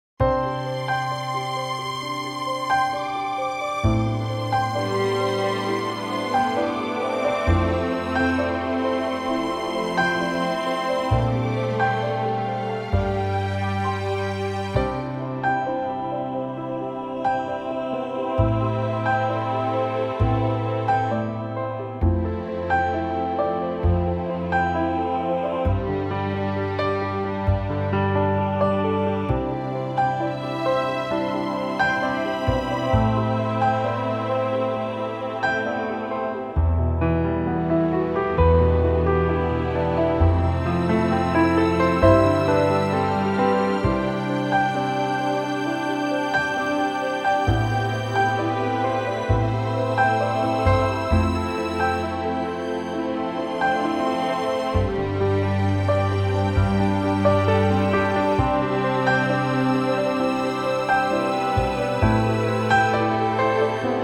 key - C - vocal range - E to G (main theme range only)